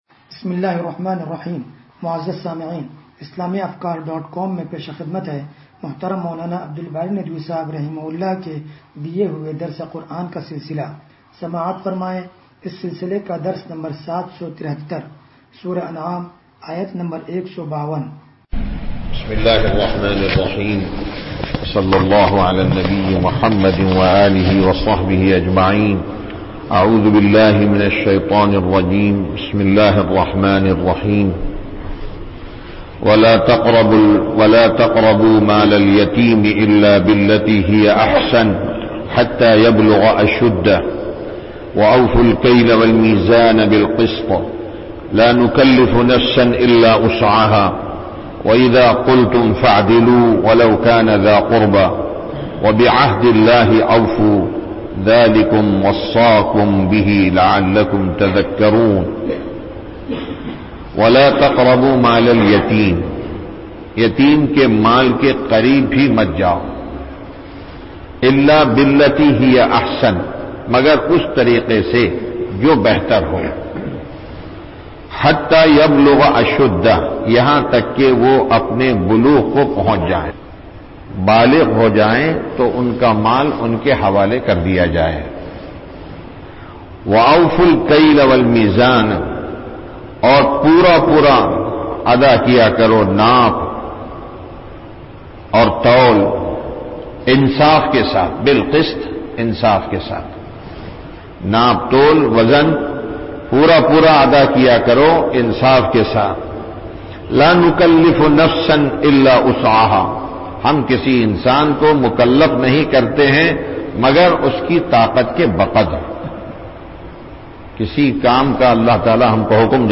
درس قرآن نمبر 0773
درس-قرآن-نمبر-0773.mp3